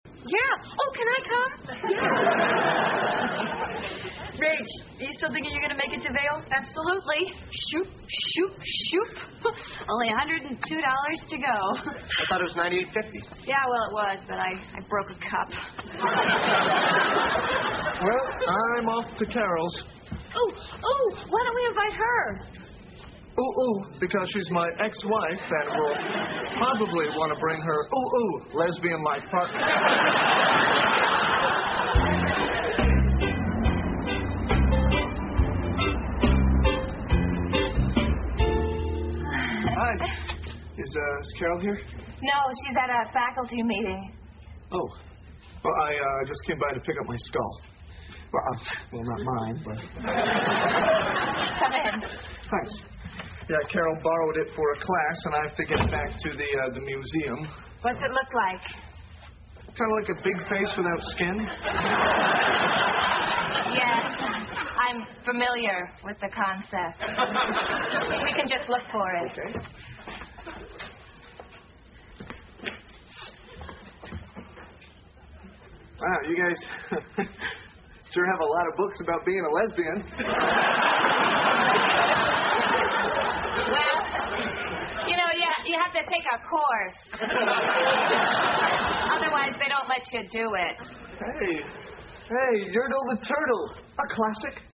在线英语听力室老友记精校版第1季 第102期:气球飞走了(3)的听力文件下载, 《老友记精校版》是美国乃至全世界最受欢迎的情景喜剧，一共拍摄了10季，以其幽默的对白和与现实生活的贴近吸引了无数的观众，精校版栏目搭配高音质音频与同步双语字幕，是练习提升英语听力水平，积累英语知识的好帮手。